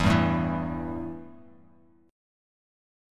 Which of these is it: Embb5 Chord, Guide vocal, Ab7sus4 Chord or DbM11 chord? Embb5 Chord